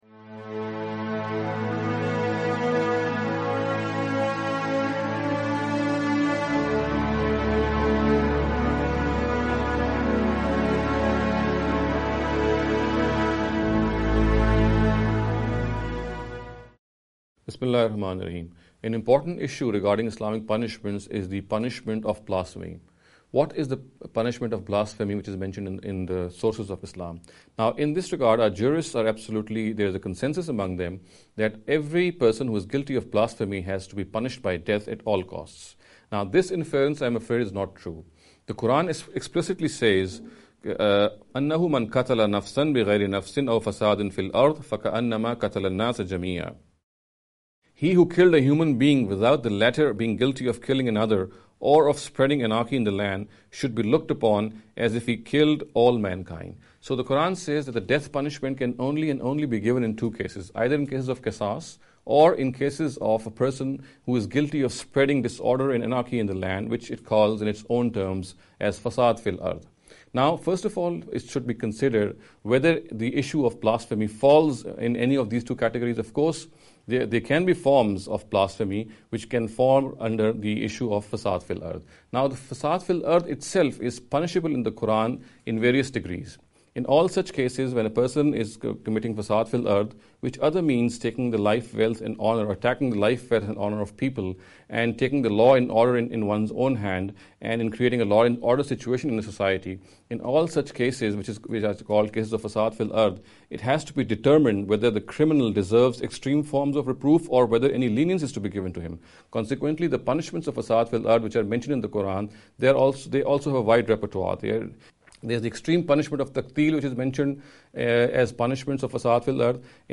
This lecture series will deal with some misconception regarding the Islamic Punishments.